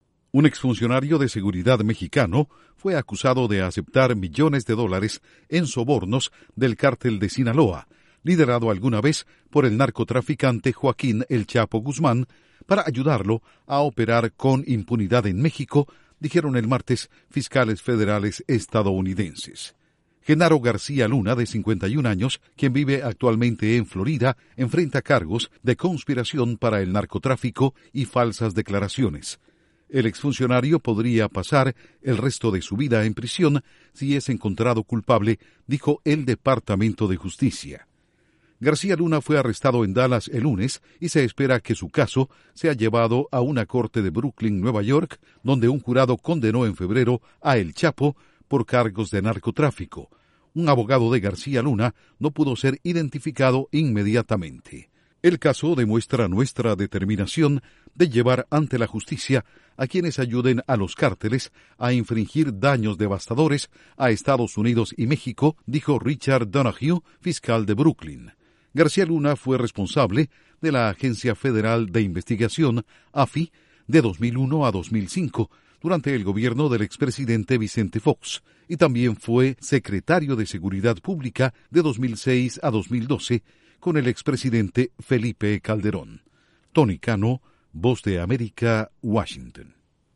Estados Unidos: Alto exfuncionario mexicano aceptó sobornos de "El Chapo". Informa desde la Voz de América en Washington